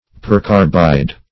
Search Result for " percarbide" : The Collaborative International Dictionary of English v.0.48: Percarbide \Per*car"bide\, n. [Pref. per- + carbide.]
percarbide.mp3